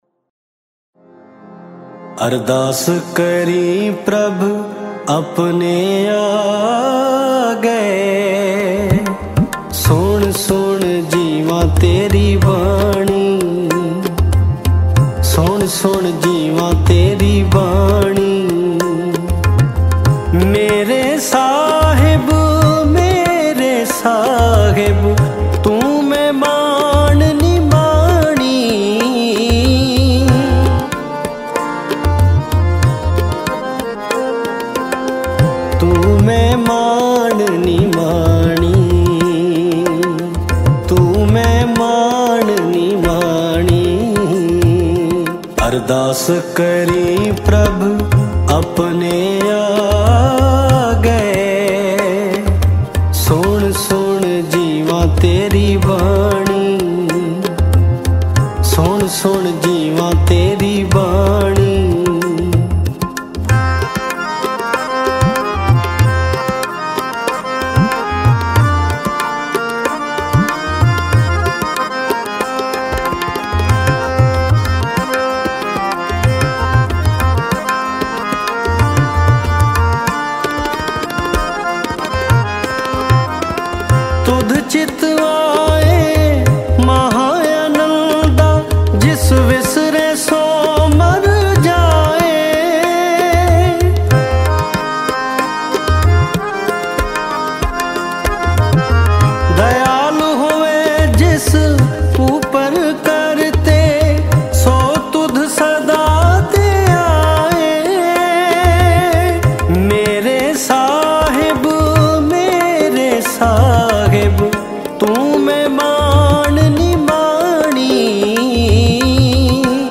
Mp3 Files / Gurbani Kirtan / 2025-Shabad Kirtan / Albums /